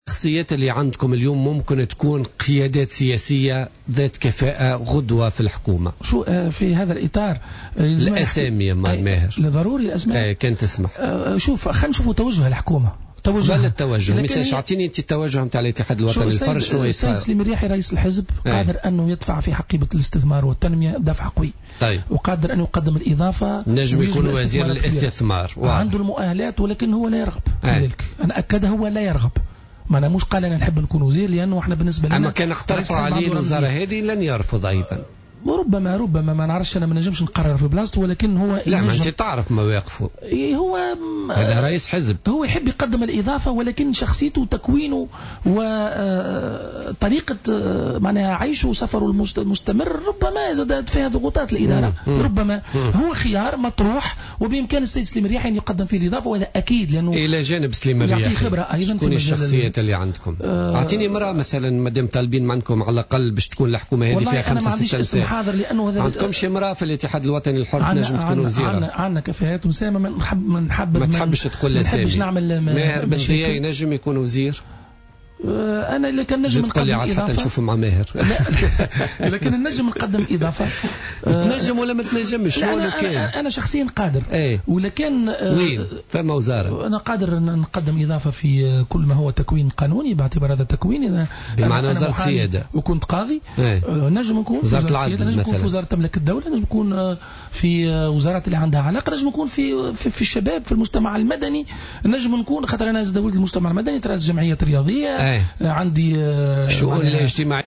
ضيف بوليتيكا اليوم الاثنين على الجوهرة أف أم